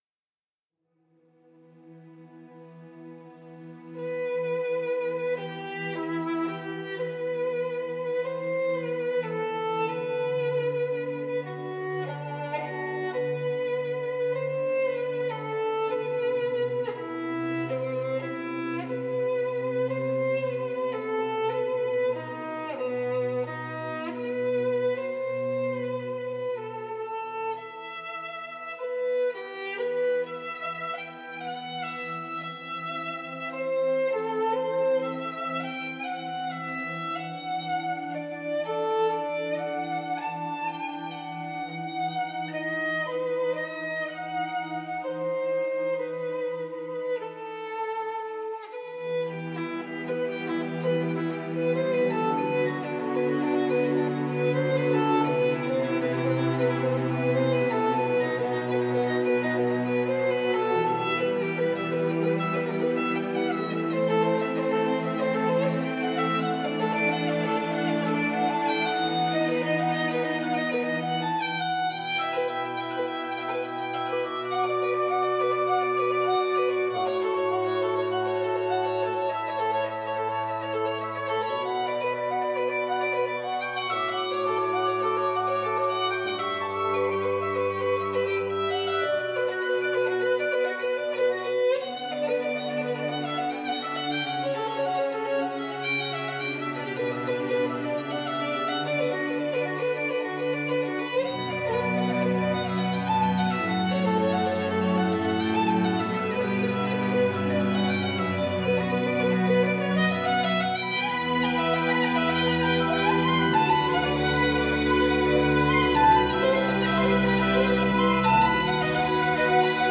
با این موسیقی ملایم و بیکلام شب را به پایان ببرید